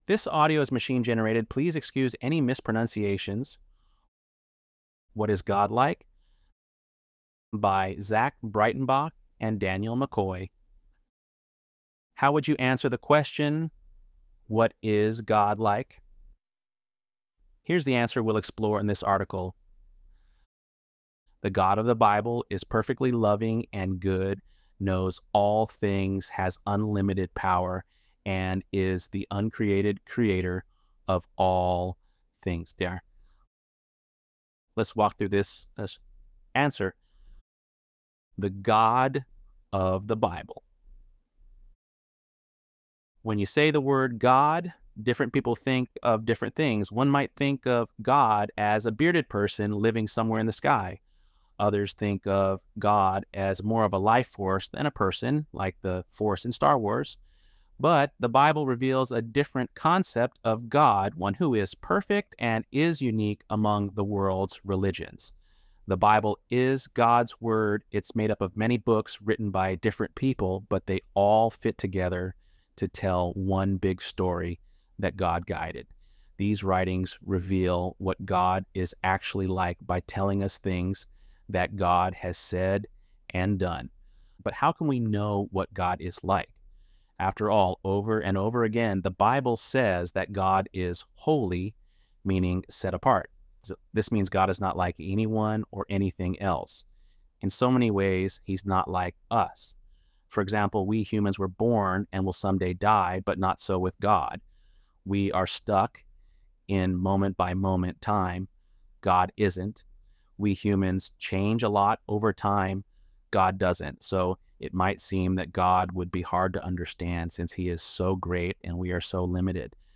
ElevenLabs_1-1.mp3